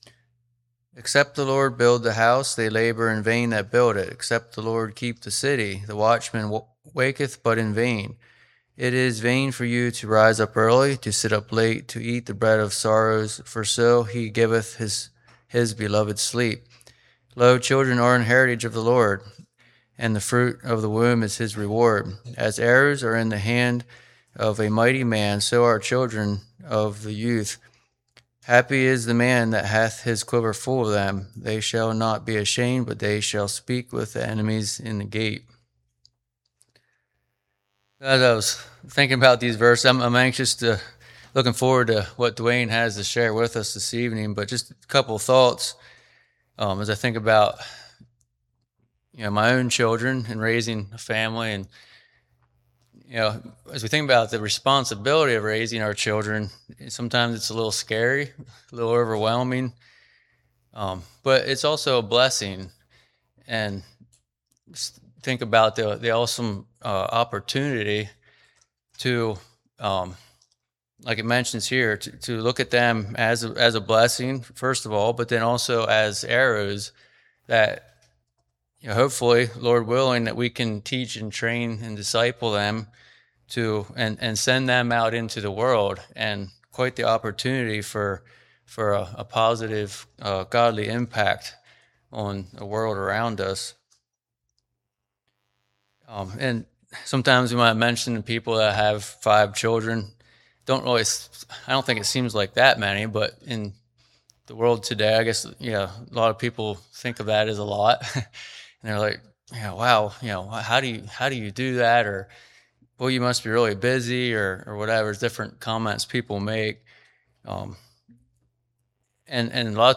Winter Bible Study: Psalms- Part 3